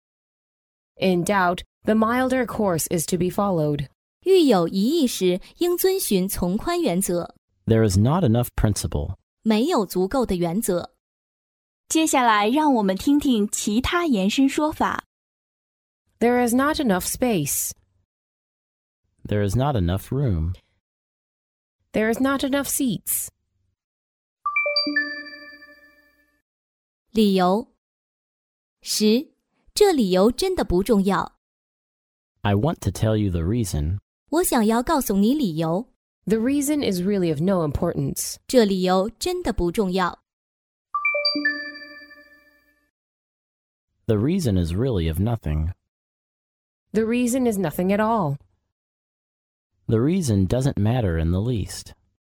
在线英语听力室法律英语就该这么说 第40期:没有足够的原则的听力文件下载,《法律英语就该这么说》栏目收录各种特定情境中的常用法律英语。真人发音的朗读版帮助网友熟读熟记，在工作中举一反三，游刃有余。